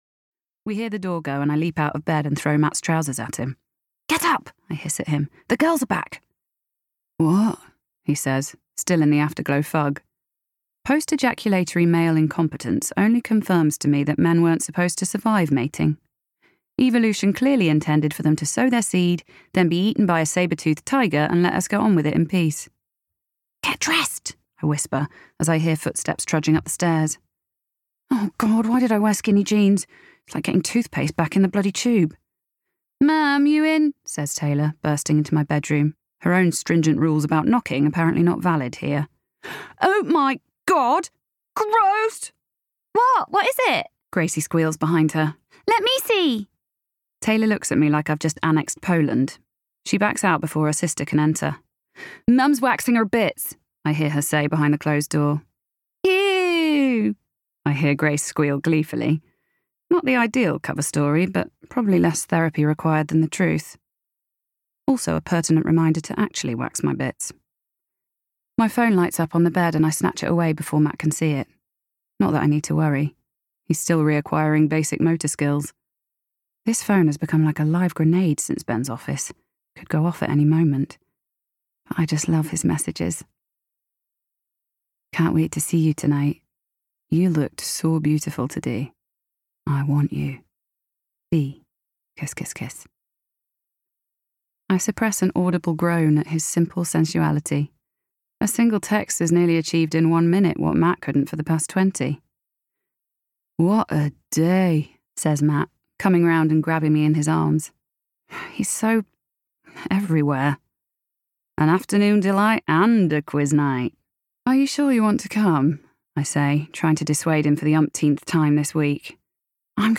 30's Neutral/ E. Midlands,
Warm/Relatable/Conversational
• Audio Books
That’ll Teach Her by Maz Evans (Neutral/West Country/Scottish/Northern) Someone Else’s Shoes (Bristol/Northern) Sense & Sensibility (RP)